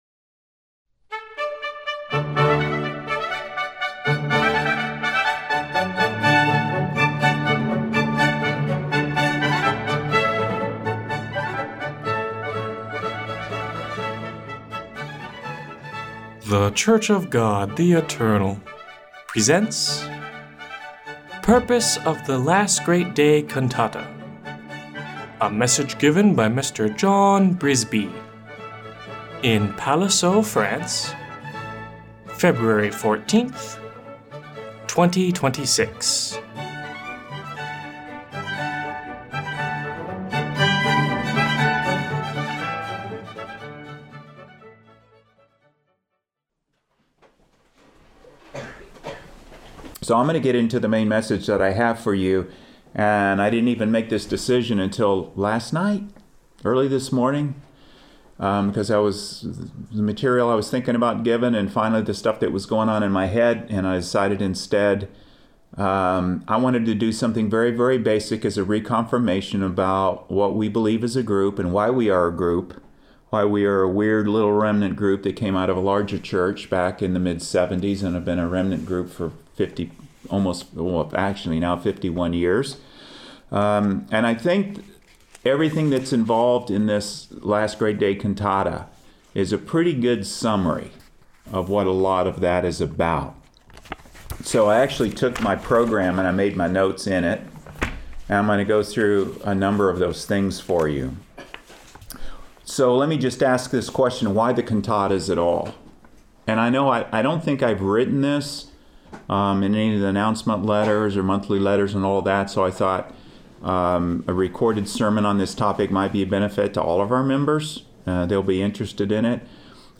This section catalogs weekly Sabbath sermons presented in Eugene, Oregon for the preceding twelve month period, beginning with the most recent.